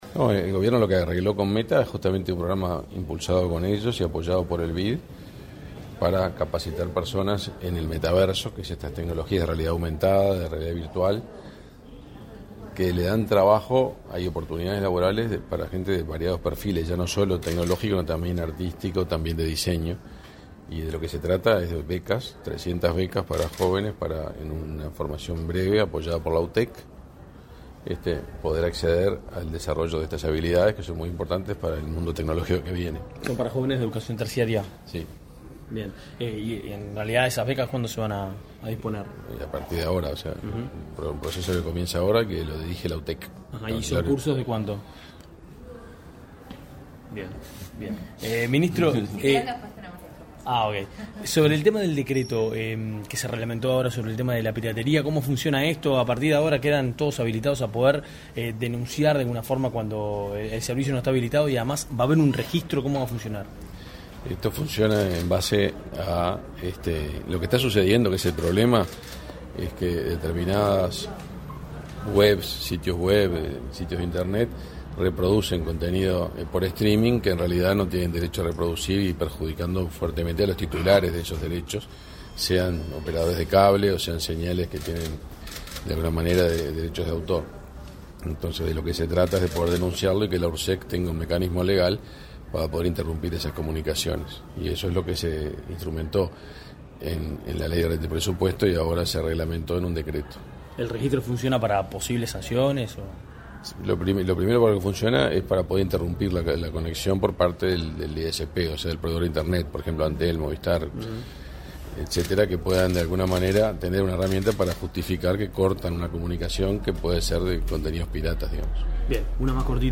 Declaraciones a la prensa del ministro de Industria, Energía y Minería, Omar Paganini
Tras el evento, el ministro realizó declaraciones a la prensa.